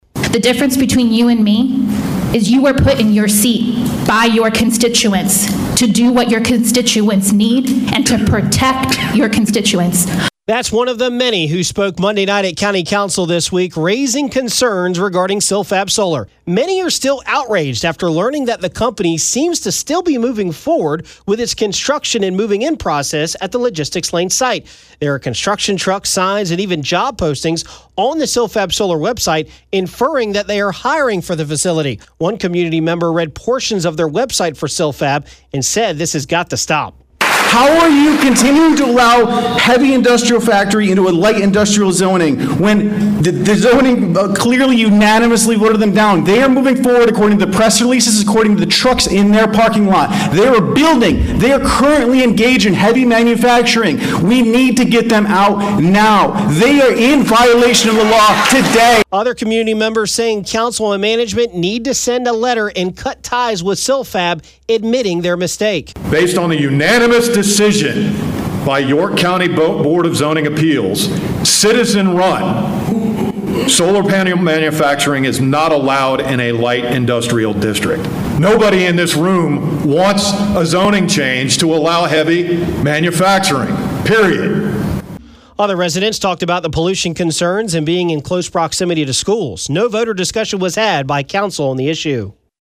AUDIO: Fort Mill residents packed Council Chambers to protest and challenge Silfab Solar